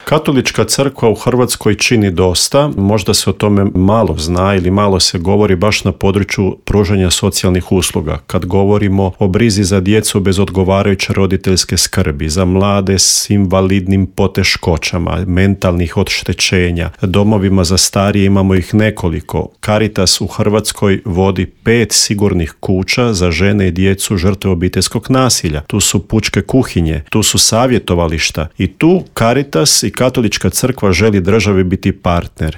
O projektu smo u Intervjuu MS-a razgovarali